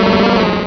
pokeemerald / sound / direct_sound_samples / cries / marowak.aif
-Replaced the Gen. 1 to 3 cries with BW2 rips.